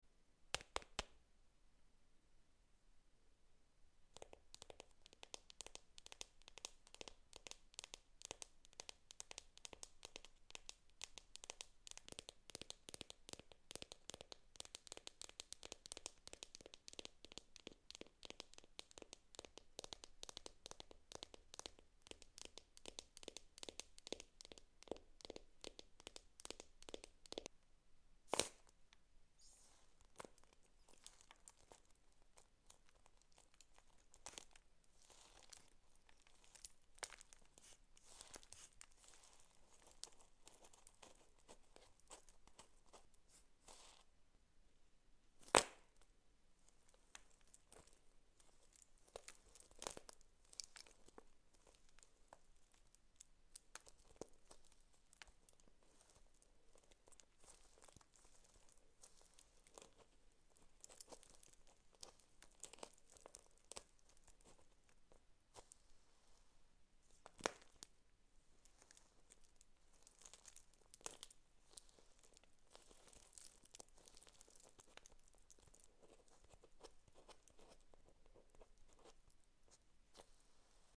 sanrio characters ASMR wax cracking